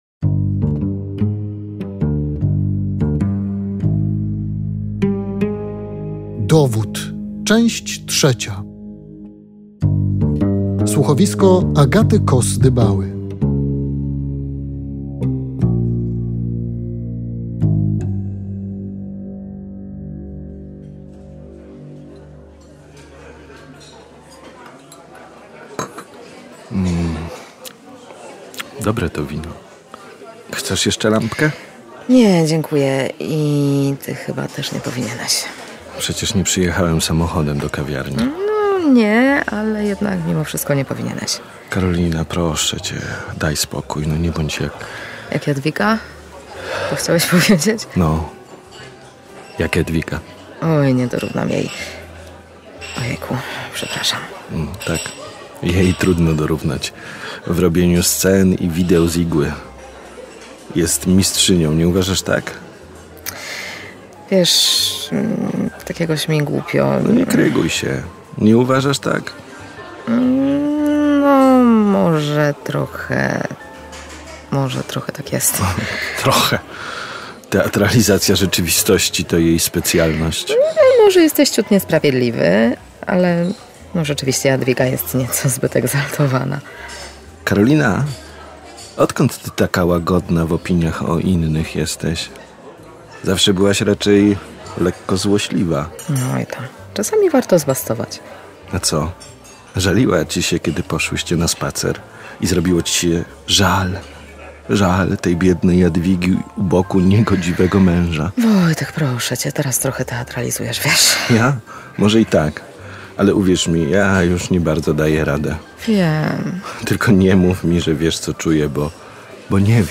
Zapraszam do wysłuchania trzeciej części słuchowiska „Dowód”.